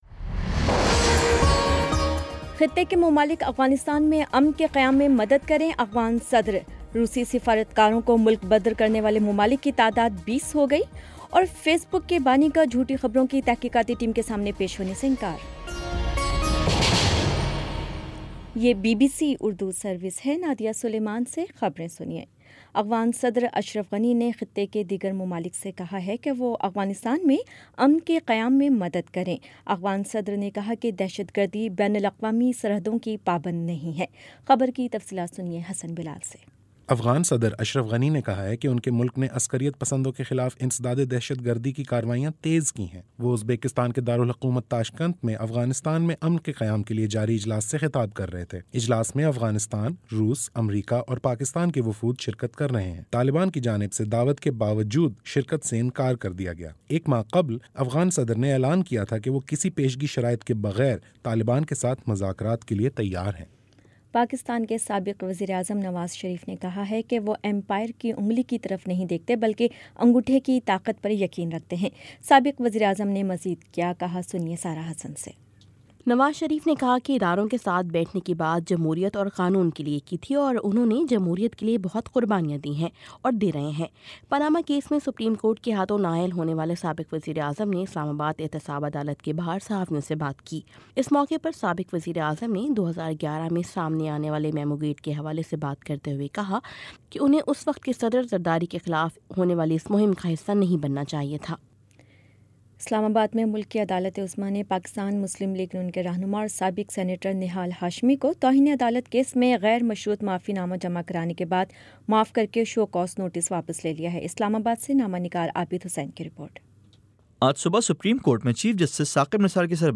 مارچ 27 : شام پانچ بجے کا نیوز بُلیٹن